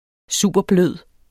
Udtale [ ˈsuˀbʌˈbløˀð ]